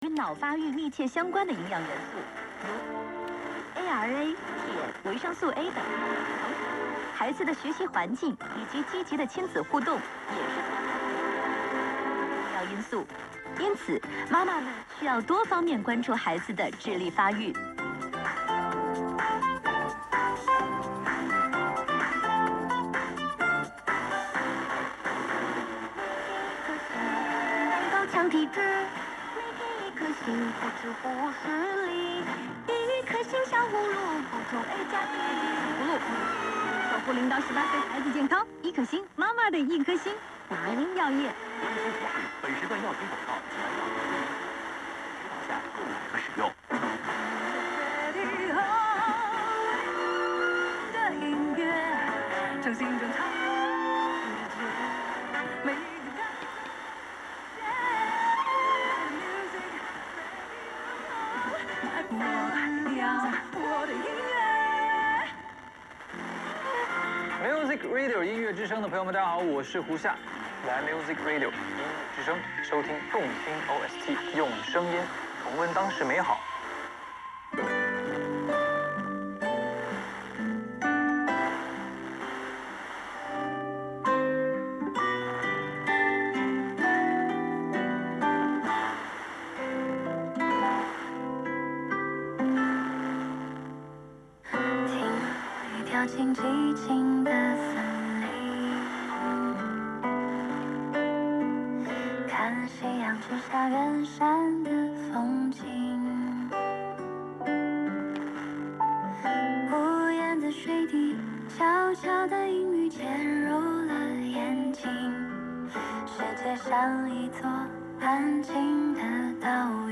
混信しているもう一方の局はなんでしょう。
<受信地：岩手県 RX:SIHUADON D-808>